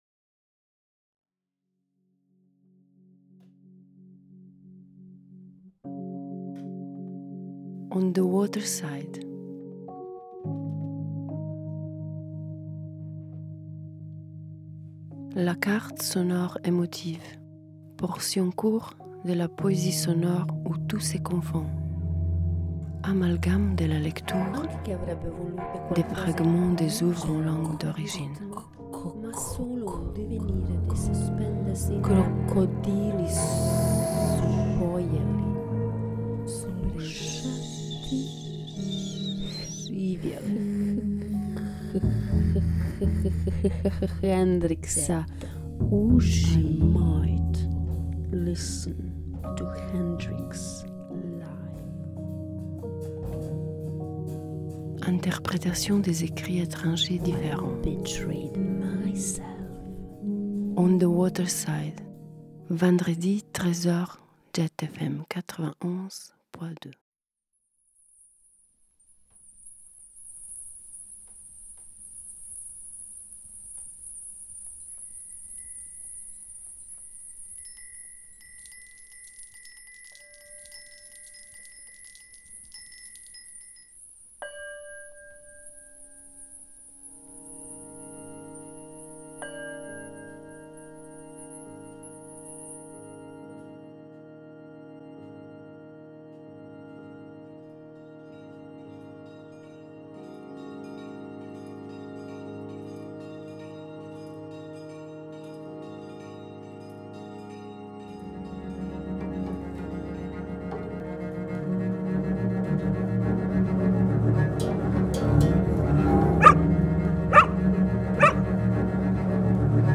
La portion court de la poésie sonore où tout se confonde.
Exploration de les sons dirty et les mélangeant avec des mélodies harmoniques. Chaque portion vous offre le son de la langue etranger superposés sur un tapis sonore crée exprès pour approcher l’ambiance.